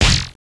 Goofy Sound Effects
Splat
Splat.WAV